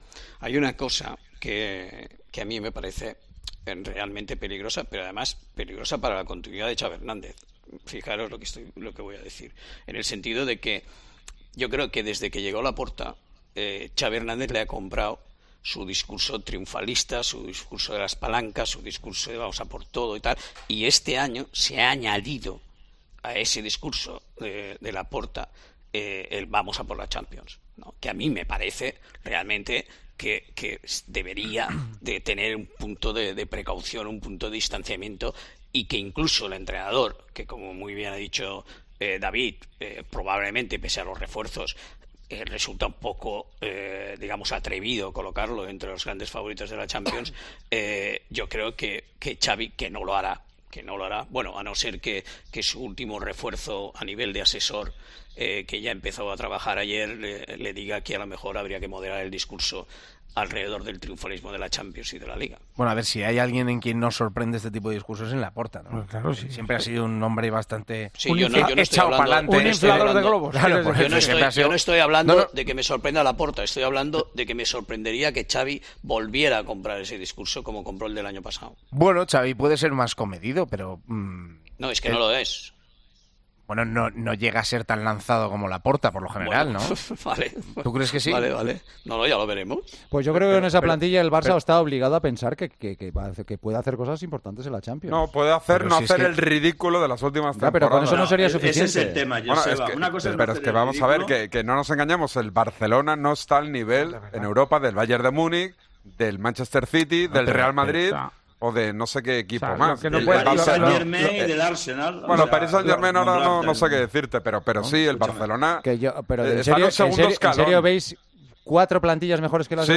El debate en El Partidazo de COPE sobre las plantillas del Real Madrid y el FC Barcelona